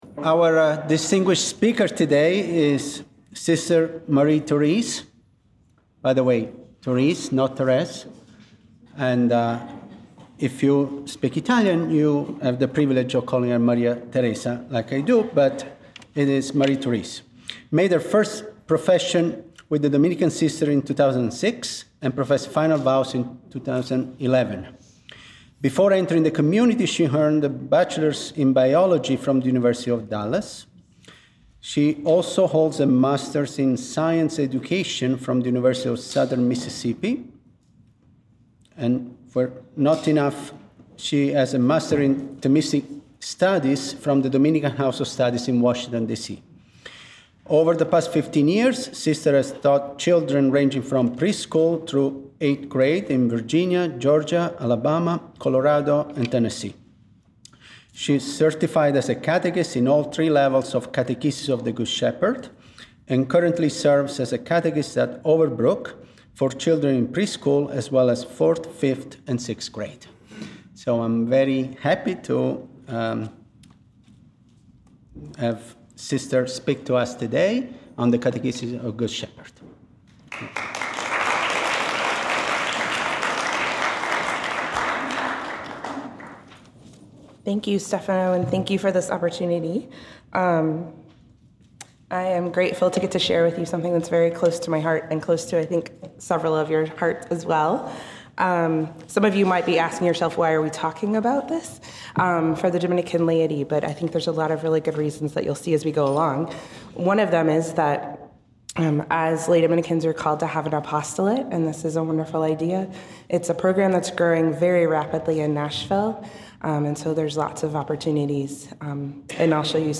Chapter Meeting